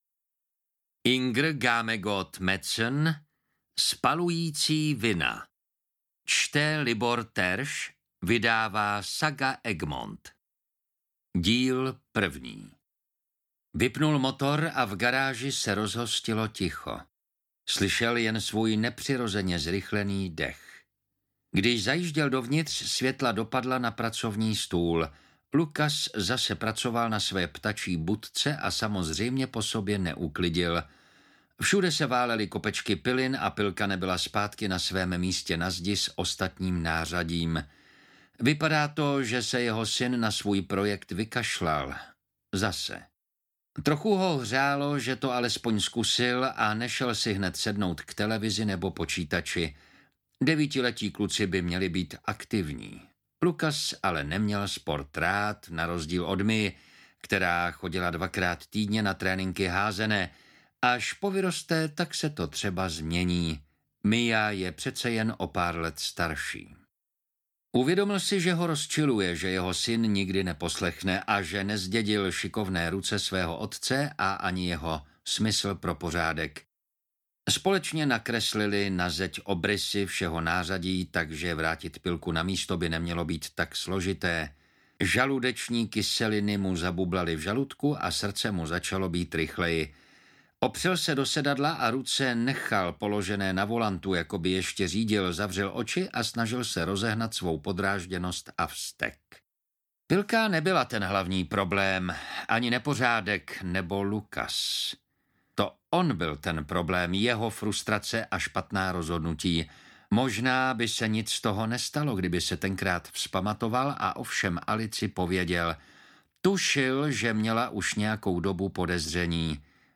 Spalující vina audiokniha
Ukázka z knihy